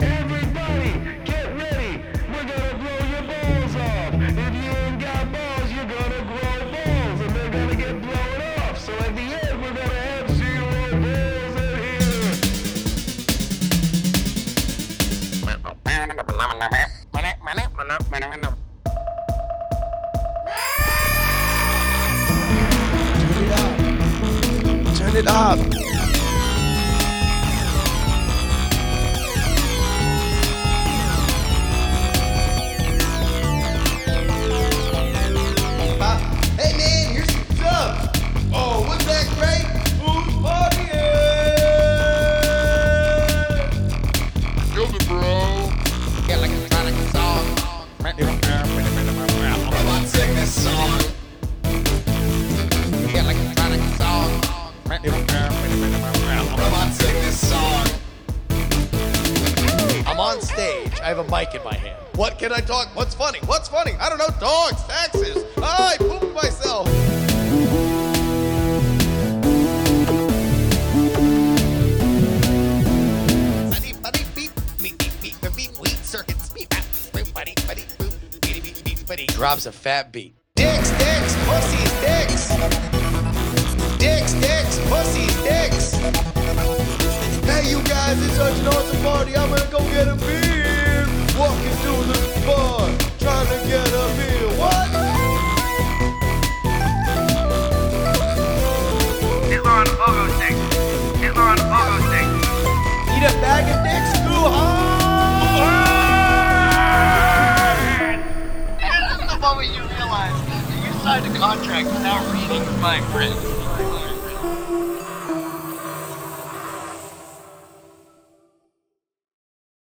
Original Club Mix https